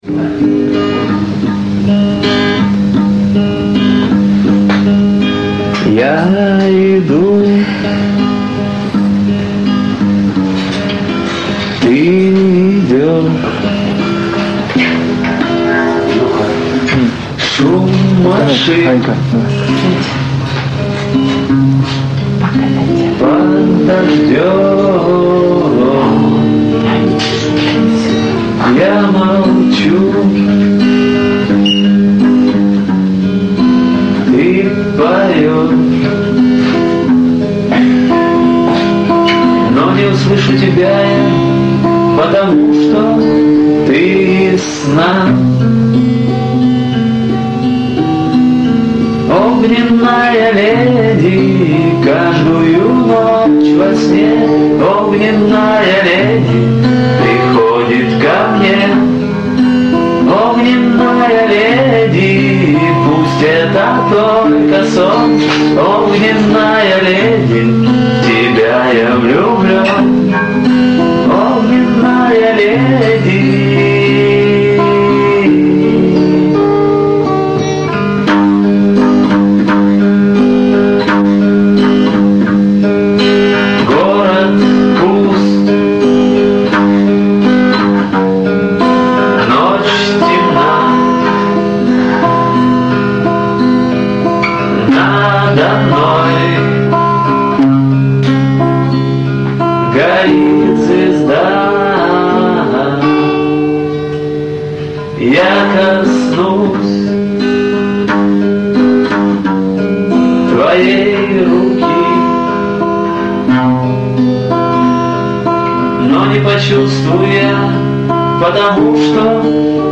К сожалению у них плохое качество записи, но лучше нету. :-(